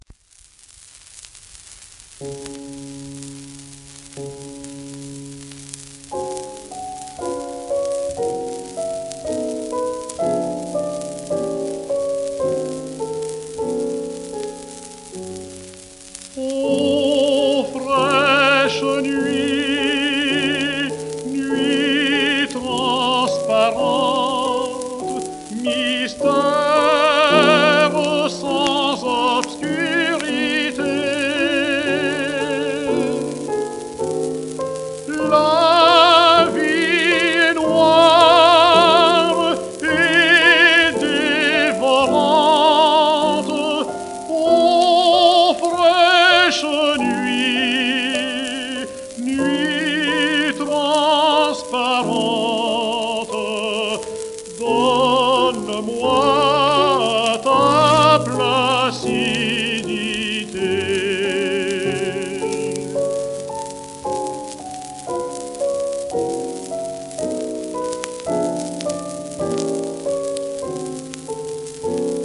フランスのテノール。